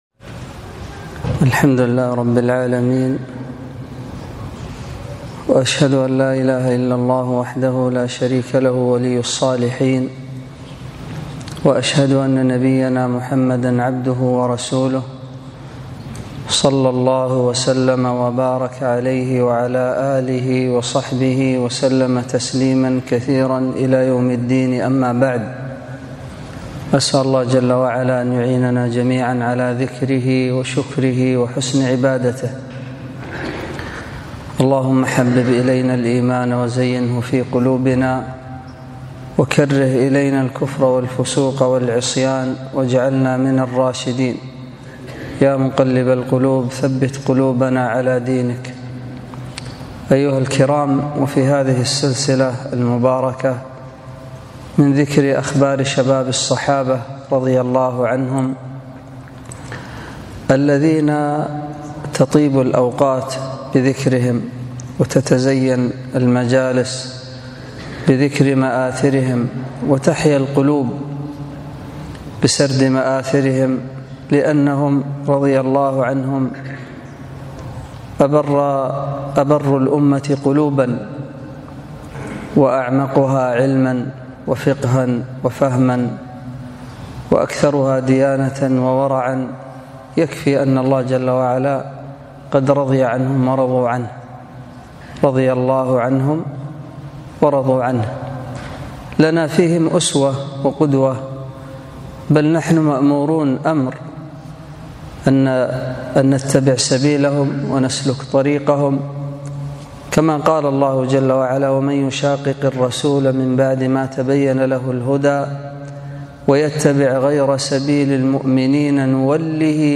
محاضرة - من سيرة الصحابي معاذ بن جبل رضي الله عنه